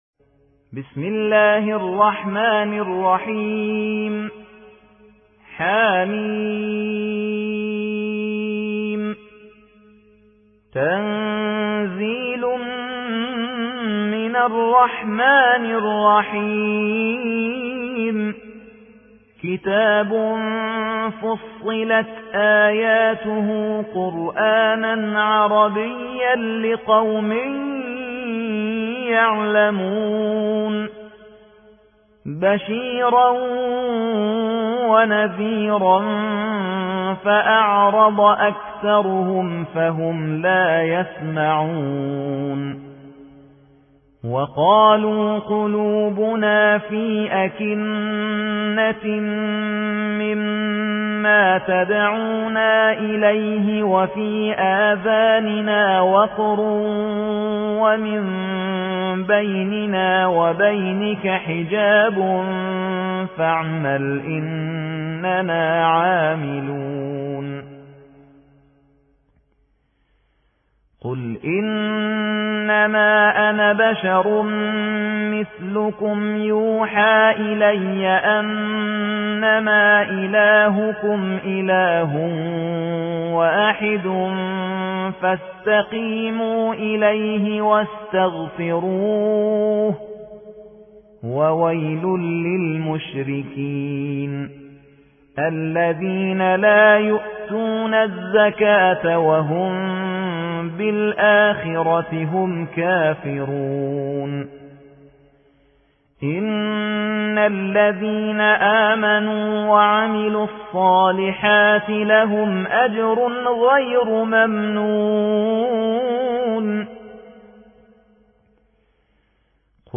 سورة فصلت / القارئ